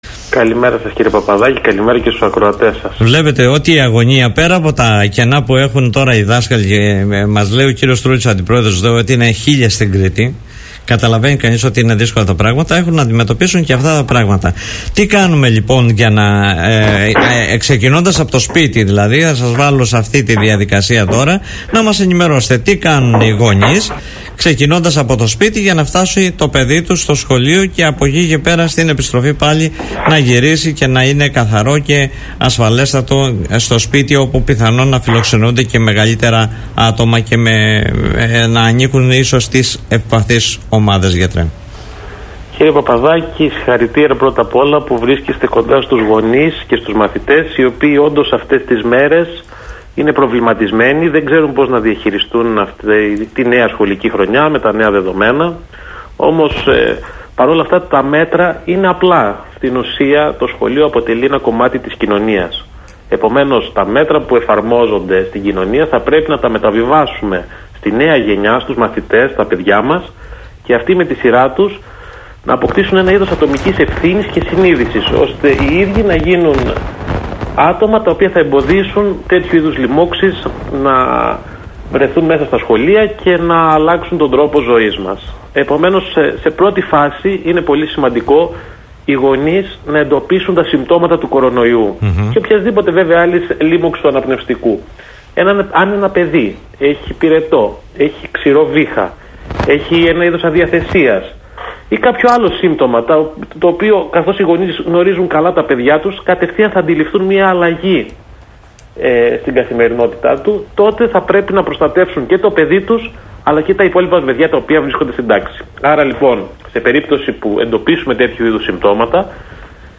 Τα απαραίτητα μέτρα για να νιώθουν ασφαλείς γονείς και μαθητές στο σχολείο παρά την πανδημία του κορονοϊού, επεσήμανε για τους ακροατές και ακροάτριες του